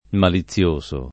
malizioso [ mali ZZL1S o ] agg.